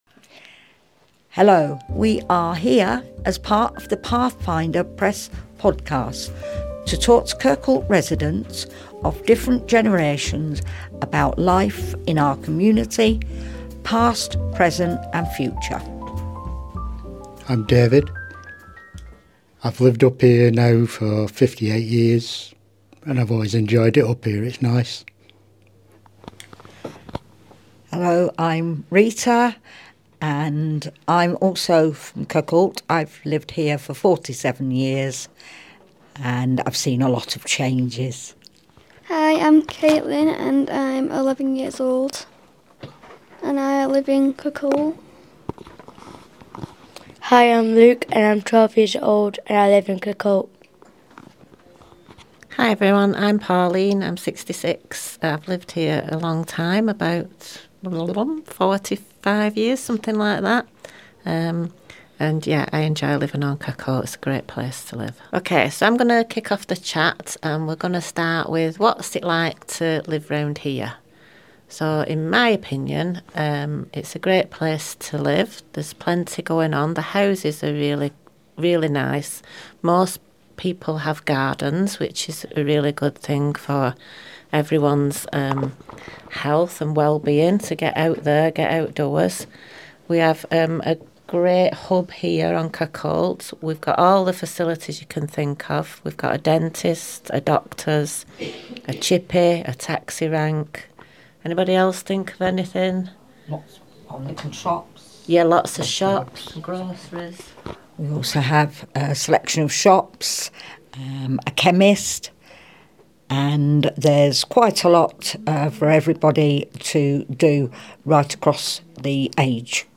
Kirkholt residents of all ages got together to talk about growing up in the area, what they like and enjoy about the area and what they would like to see change and hopes for the future.